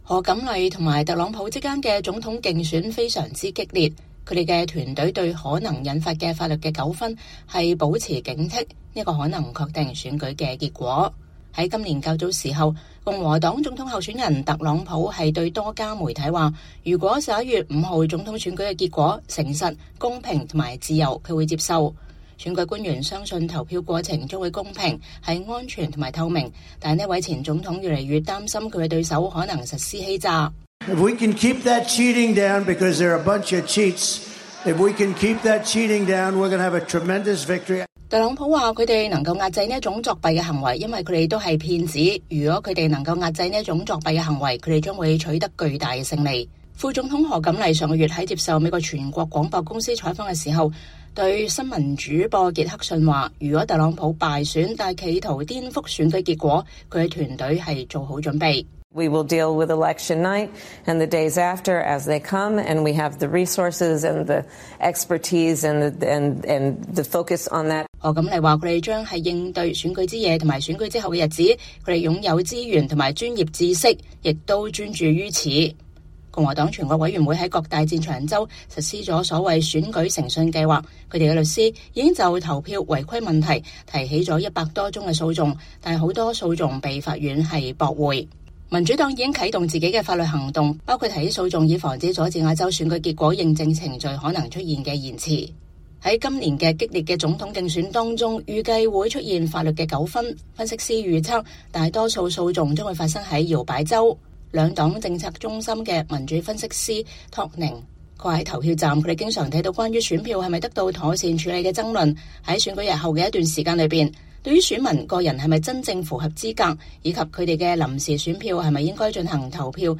他透過 Skype 發表了演說。